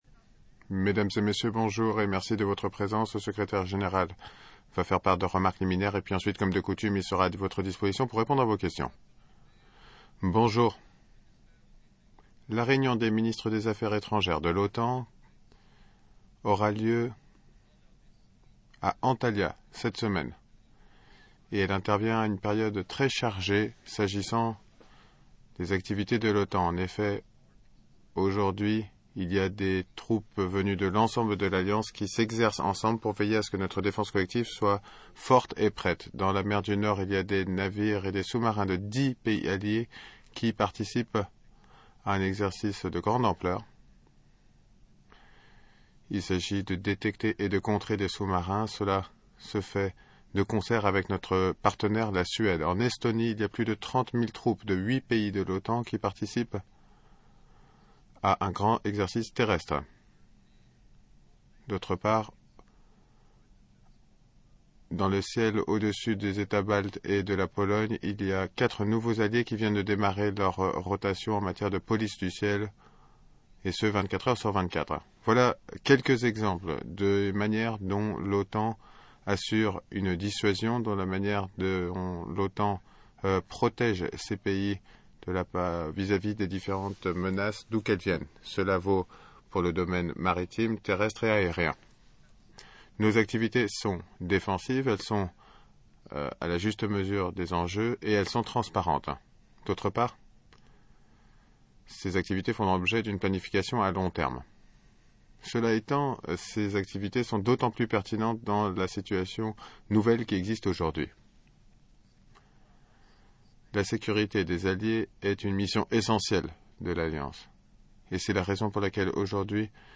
Pre-ministerial press conference by NATO Secretary General Jens Stoltenberg